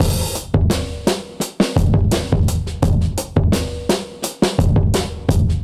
Index of /musicradar/dusty-funk-samples/Beats/85bpm/Alt Sound
DF_BeatA[dustier]_85-01.wav